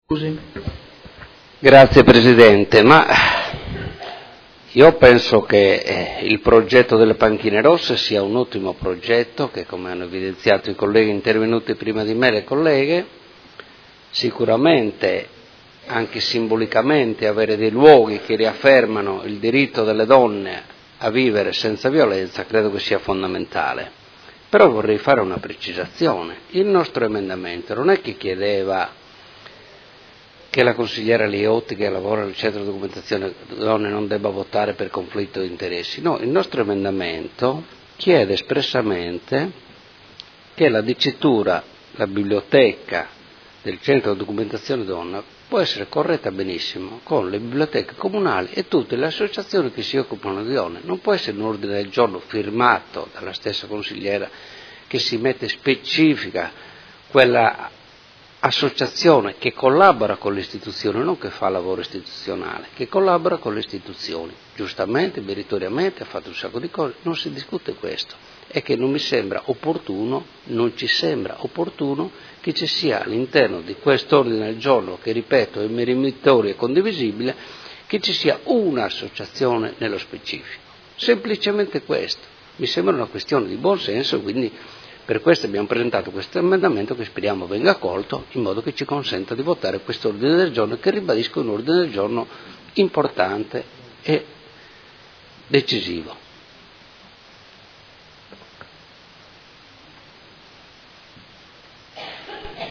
Seduta del 14/12/2017 Dibattito. Mozione presentata dai Consiglieri Poggi, Arletti, Liotti, Lenzini, Morini, Venturelli, Di Padova, Forghieri, Pacchioni, Fasano e De Lillo (PD) avente per oggetto: Progetto “Panchine rosse” – Percorso di sensibilizzazione e di informazione per sensibilizzare la città e concorrere alle azioni di prevenzione e contrasto dell’eliminazione della violenza contro le donne